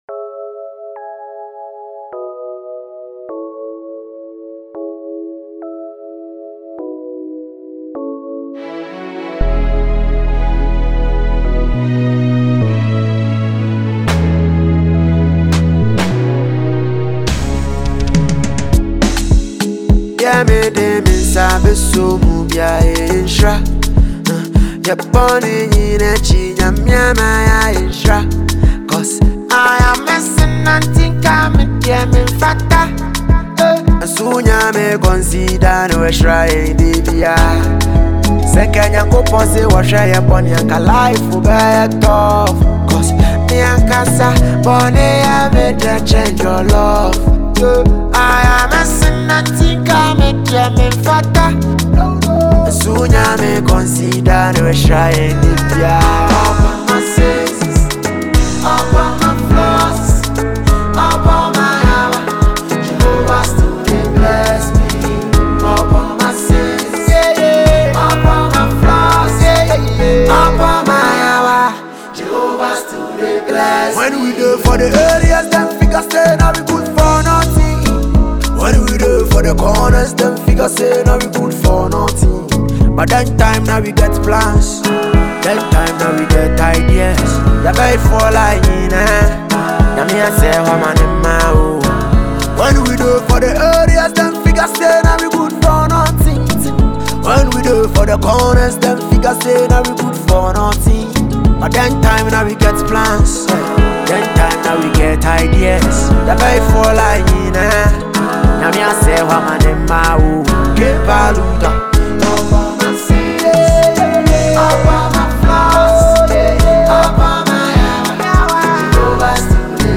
a versatile singer and rapper
a solo track